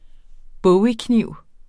Udtale [ ˈbɔwi- ]